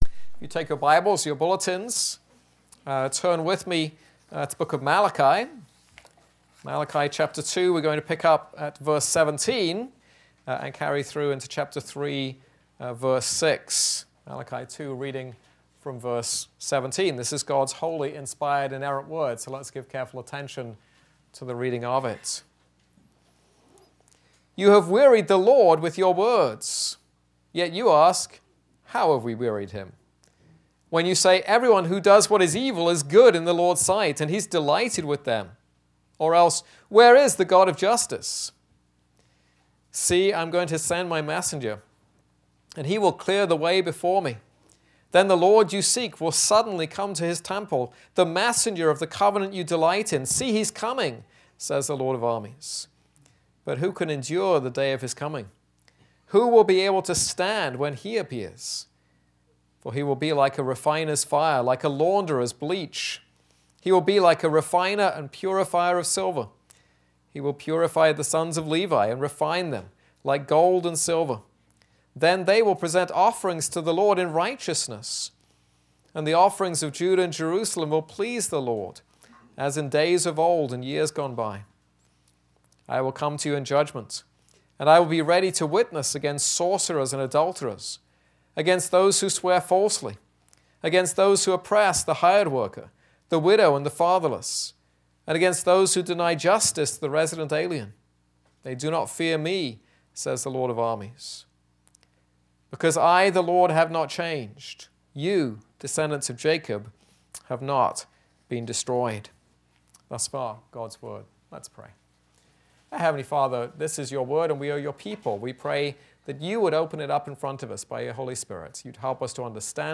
This is a sermon on Malachi 2:17-3:6.